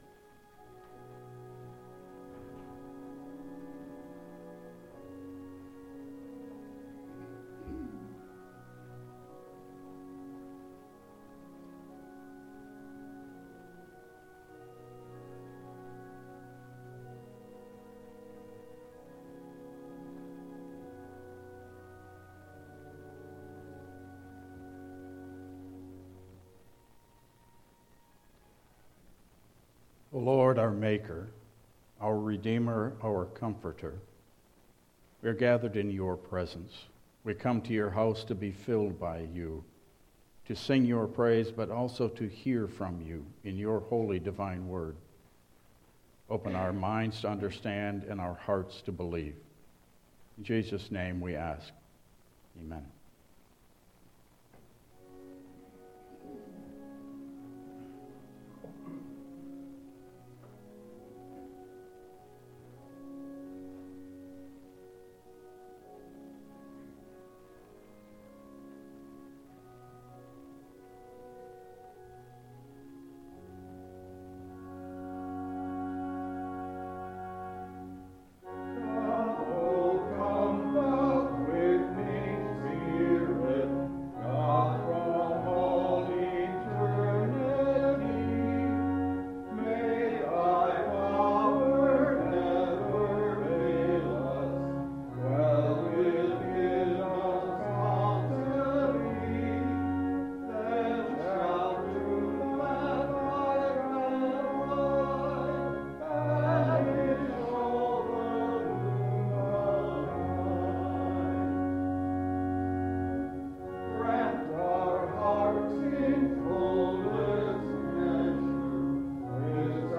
Passage: Matthew 16:21-26 Service Type: Regular Service